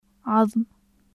/ð/を発音するときのように舌先に上の歯を軽く当てたまま，奥舌を口蓋垂に近づけて発音される/ð̩/ [ðʶ] の音です。